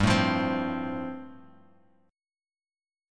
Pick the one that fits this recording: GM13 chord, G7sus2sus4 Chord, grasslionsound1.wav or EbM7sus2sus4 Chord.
G7sus2sus4 Chord